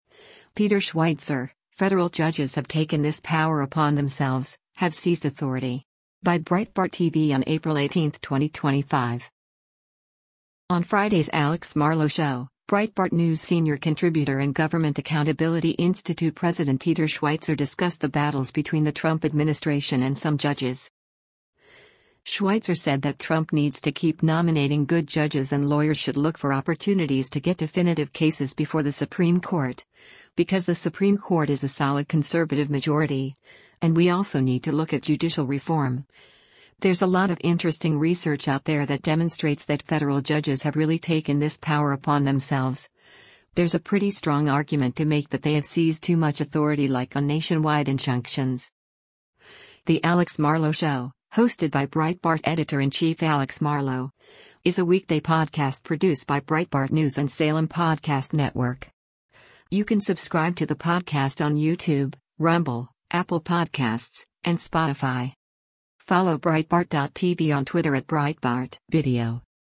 On Friday’s “Alex Marlow Show,” Breitbart News Senior Contributor and Government Accountability Institute President Peter Schweizer discussed the battles between the Trump administration and some judges.
“The Alex Marlow Show,” hosted by Breitbart Editor-in-Chief Alex Marlow, is a weekday podcast produced by Breitbart News and Salem Podcast Network.